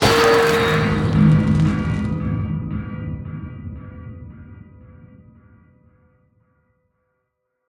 thunder2.ogg